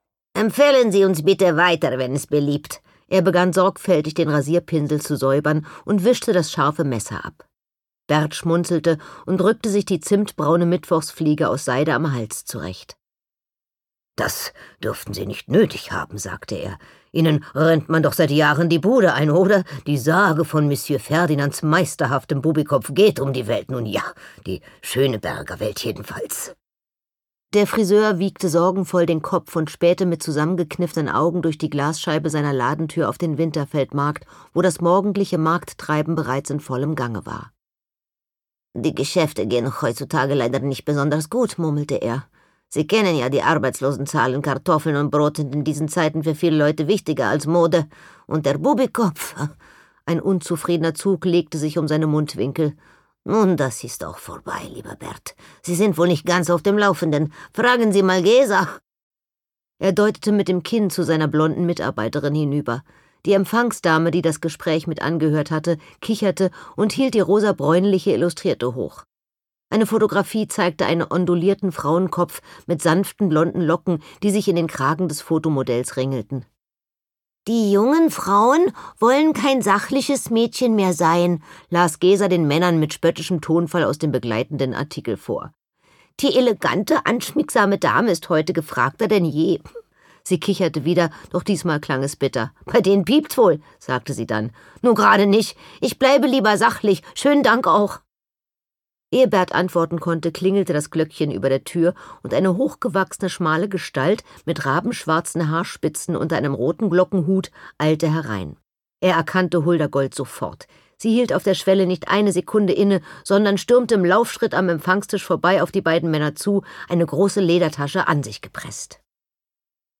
Fräulein Gold: Nacht über der Havel Anne Stern (Autor) Anna Thalbach (Sprecher) Audio Disc 2024 | 1.